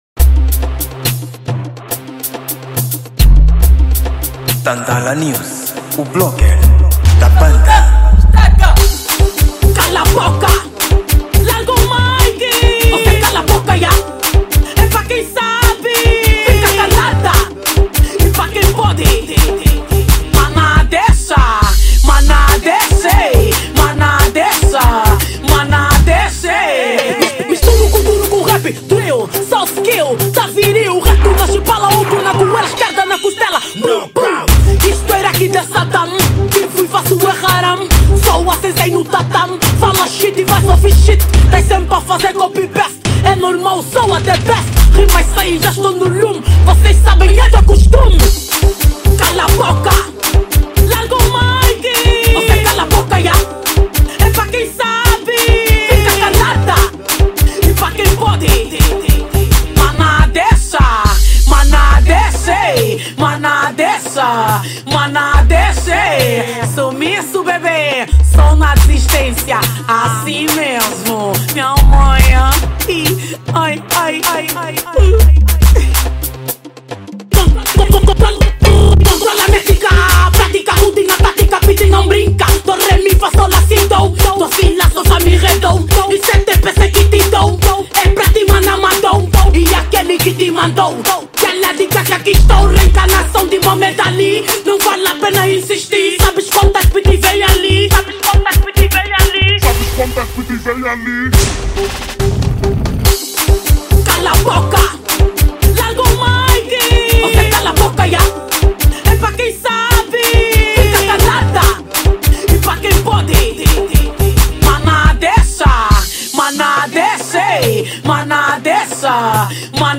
Gênero: Kuduro